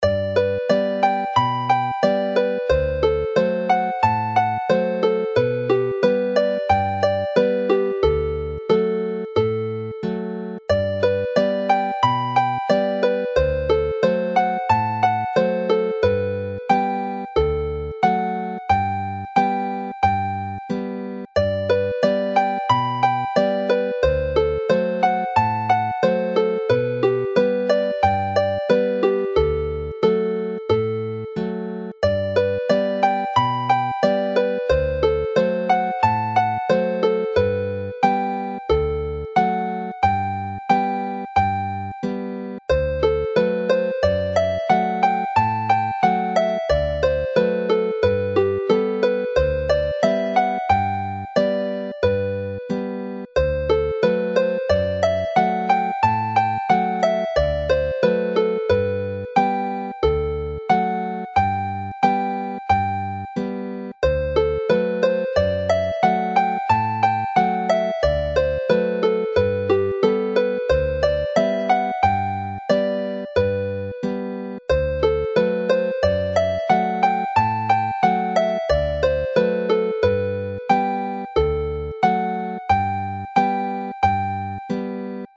Llanthony Abbey is a dance tune named after the famous abbey near Abergavenny.
Play the tune slowly